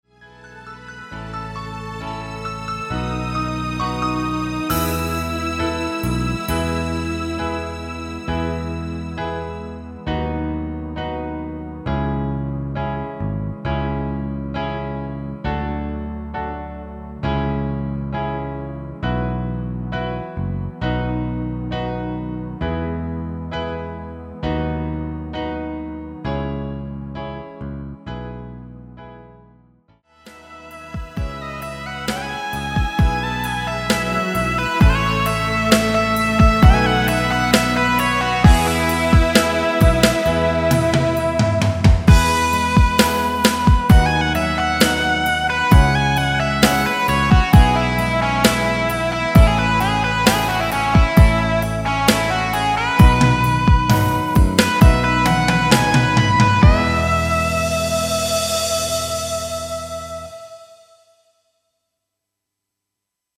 MR입니다.
엔딩이 페이드 아웃이라 라이브 하시기 좋게 엔딩을 만들어 놓았습니다.(미리듣기 참조)
앞부분30초, 뒷부분30초씩 편집해서 올려 드리고 있습니다.
중간에 음이 끈어지고 다시 나오는 이유는